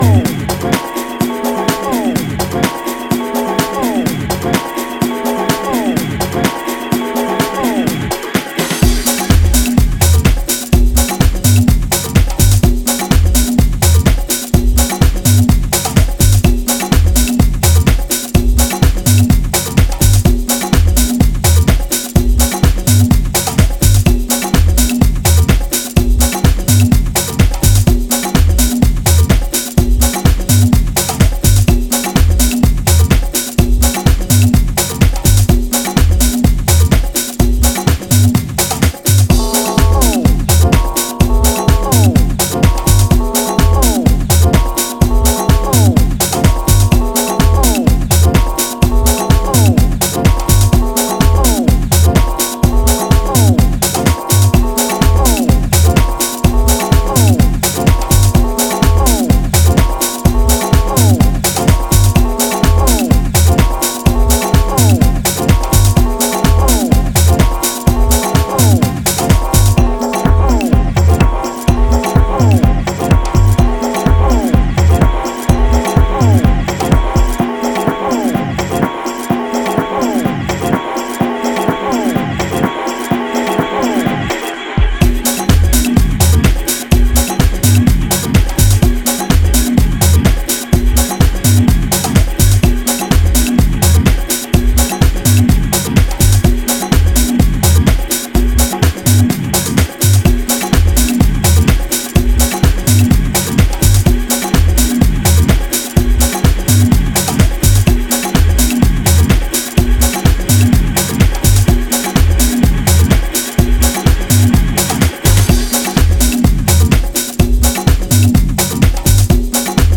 lover of Afro beats and his Latin influences.